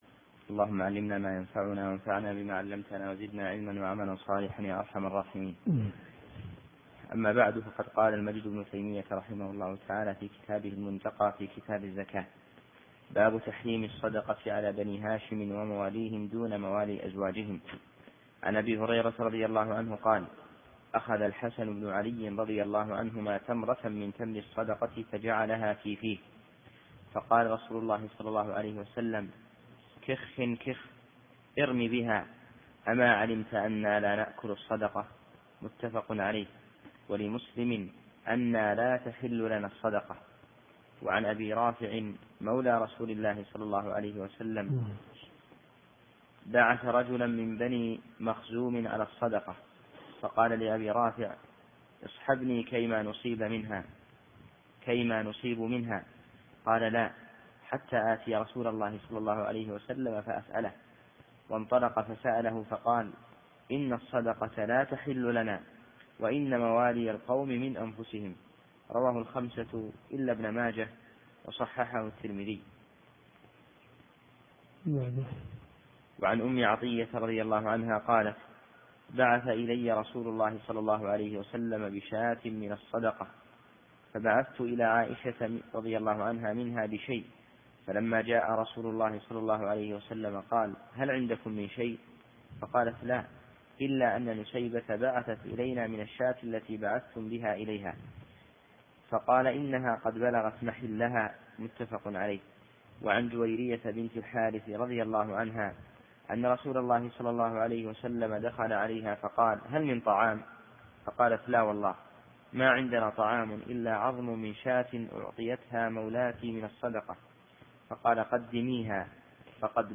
منتقى الاخبار كتاب الزكاة من حديث 2070 إلى حديث 2093 . دورة صيفية في مسجد معاذ بن جبل .